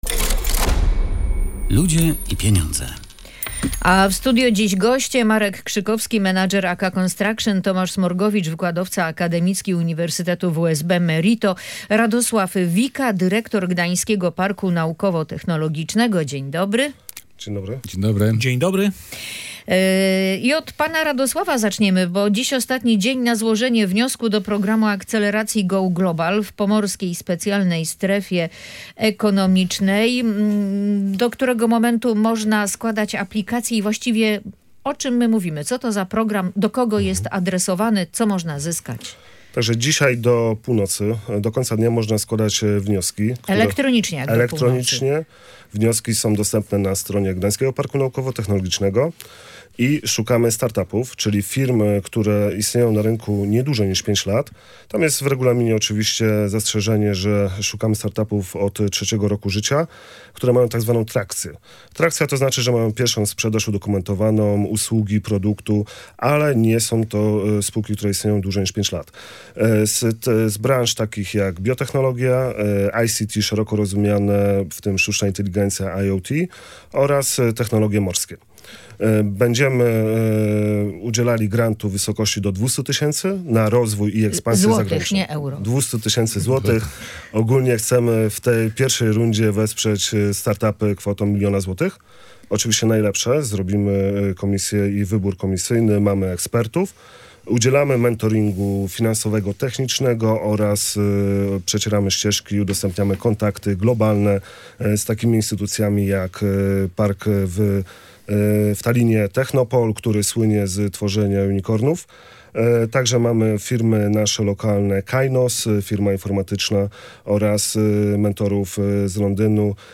Gośćmi audycji „Ludzie i Pieniądze”